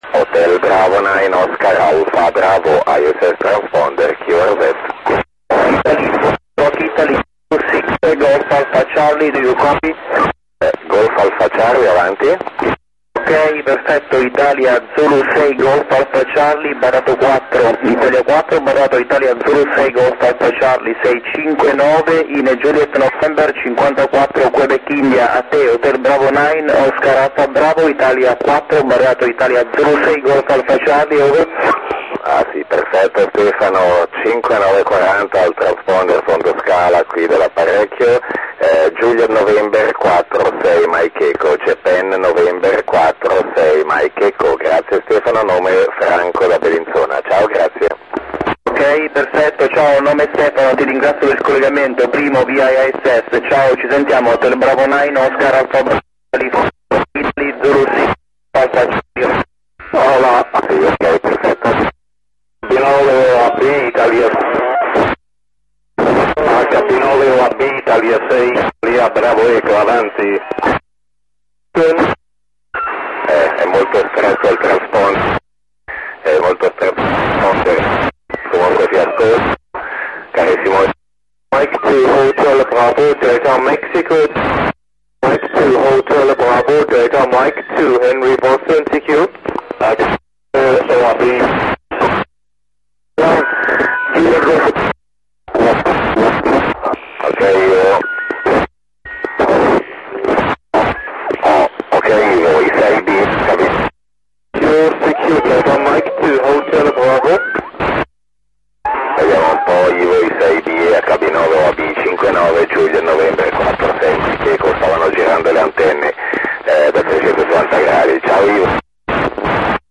modo operativo VOCE - uplink 437.800 Mhz FM , downlink 145.800 Mhz FM + - Doppler